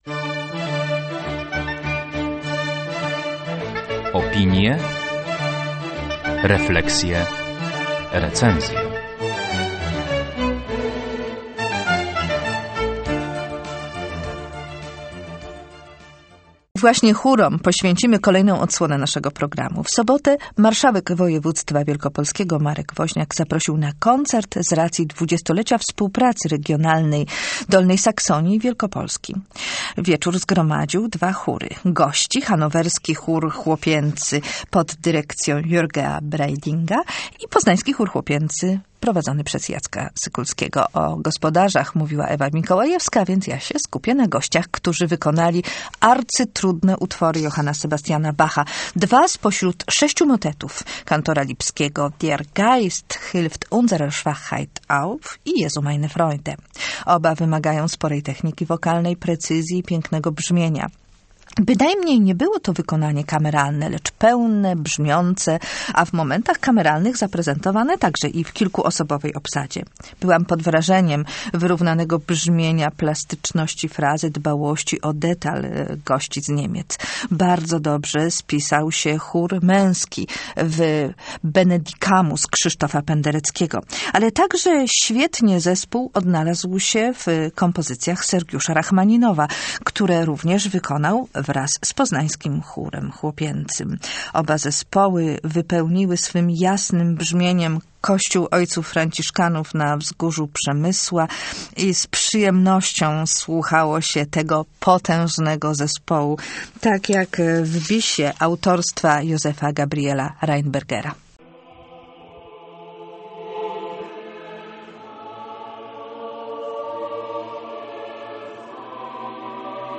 Hanower i Poznań - chóralnie